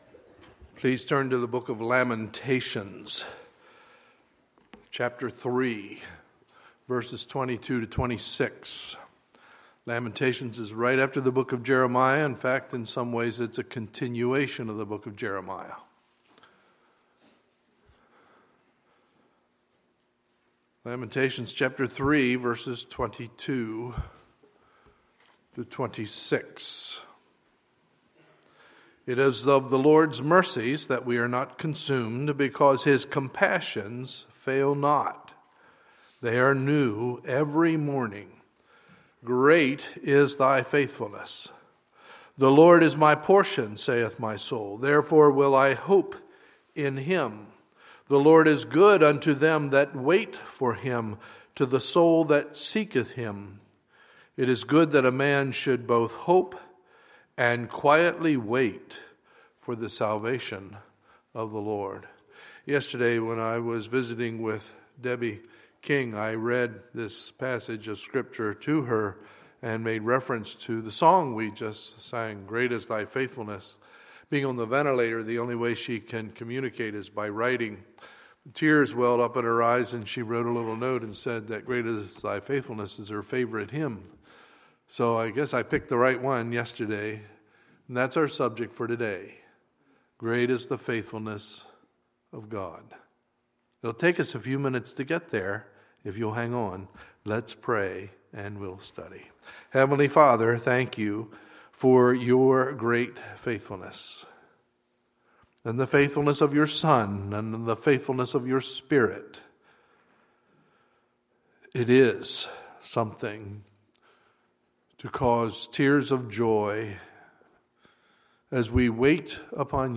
Sunday, November 29, 2015 – Sunday Morning Service